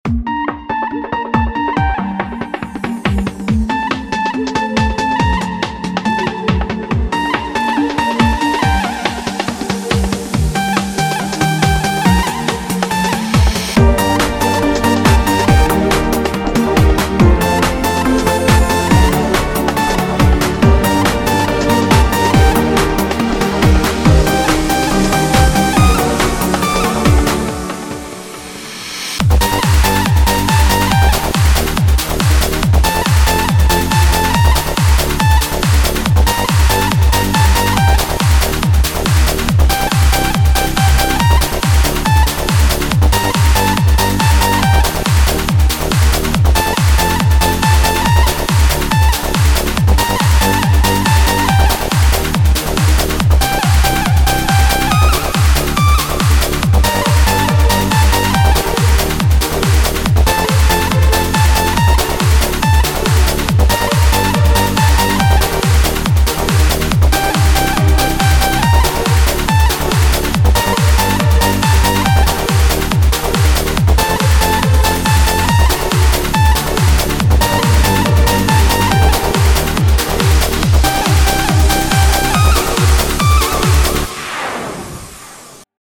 Классный ремикс